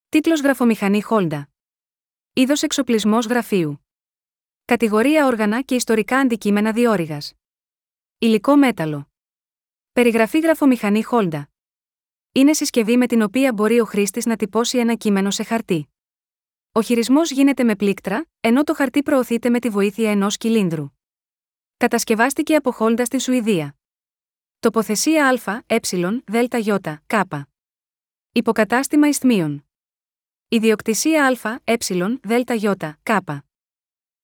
Γραφομηχανή HALDA